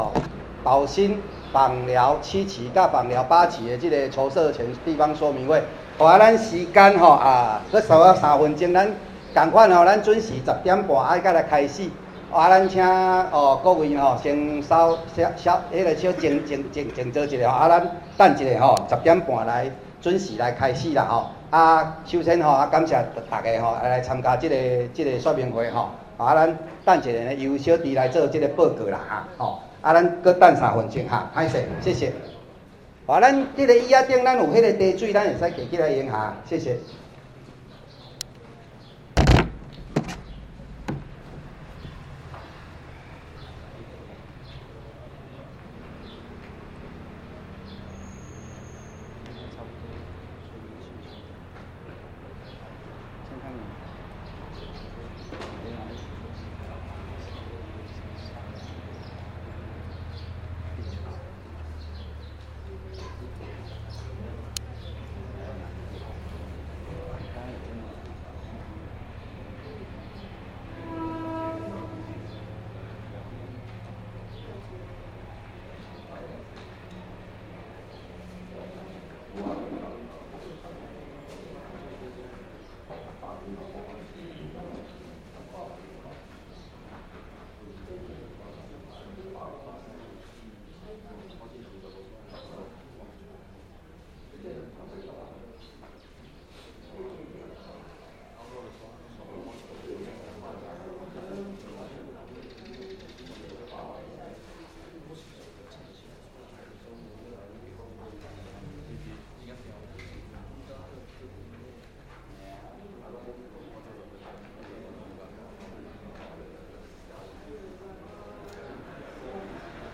寶興能源 - 寶興金榮太陽光電發電系統工程 第七期、第八期籌設前地方說明會 - 2025-12-30